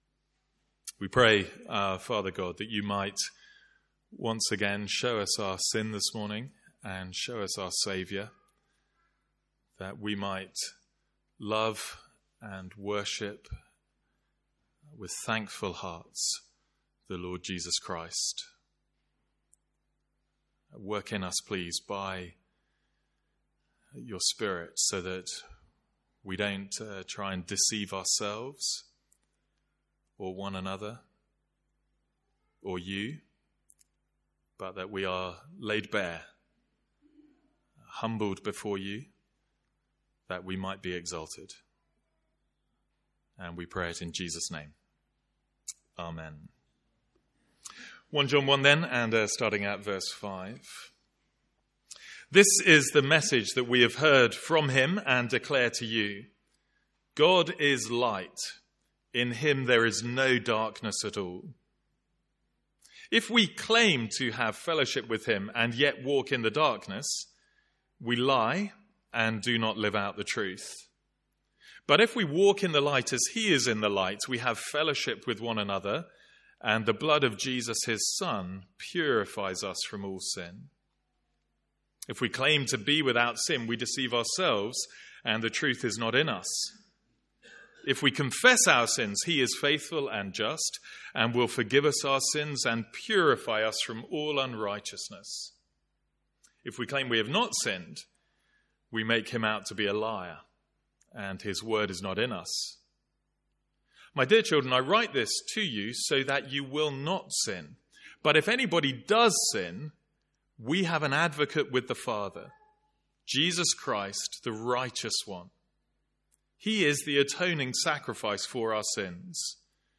Sermons | St Andrews Free Church
From our morning series in the Big Promises of God.